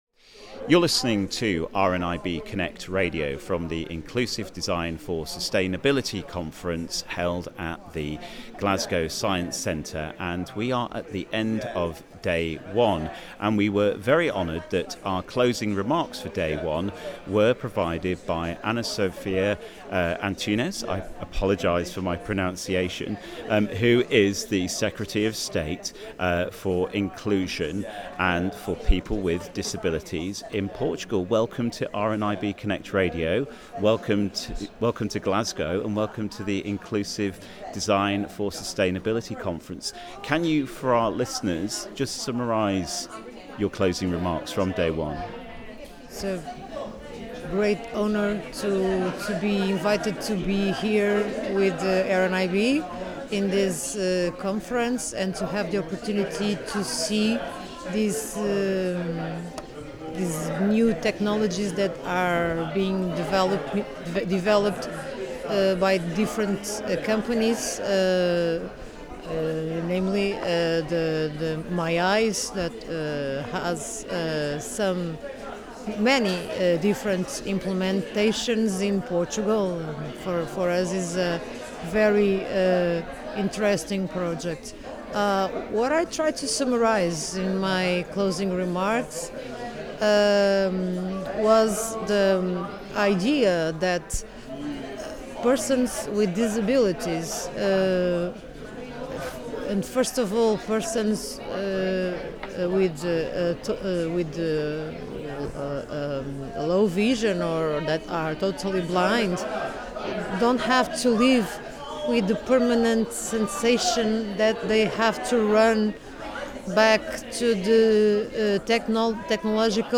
spoke with Portugal's Secretary of State for the Inclusion of Persons with Disabilities, Ana Sofia Antunes, who was one of the keynote speakers at the event.